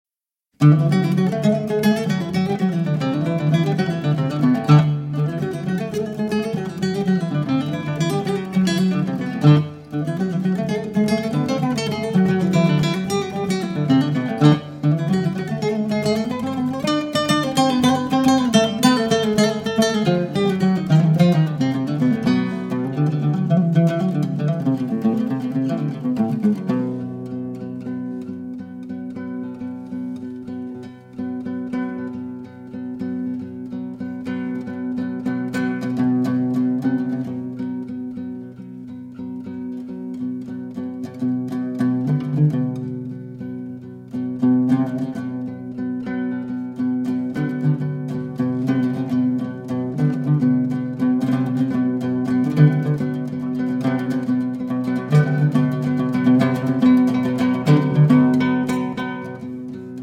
Guitar
Oud